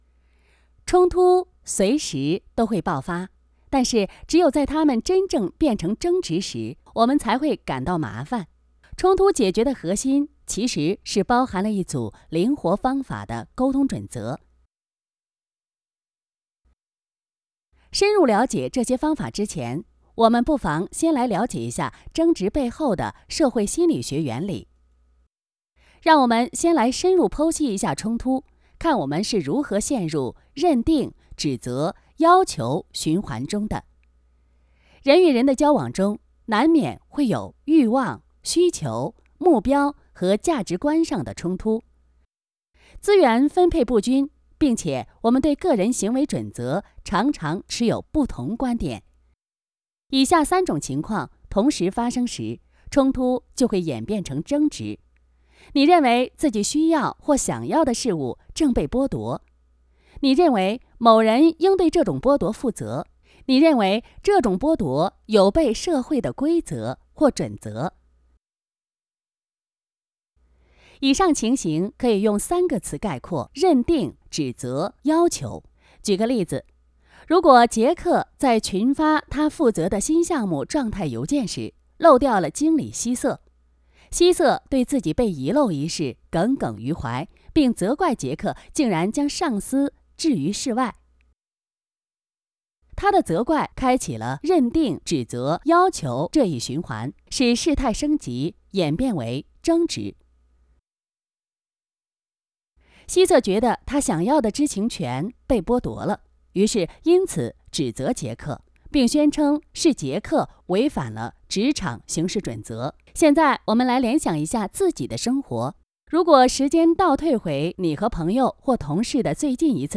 Chinese_Female_001VoiceArtist_40Hours_High_Quality_Voice_Dataset
E-learning Style Sample.wav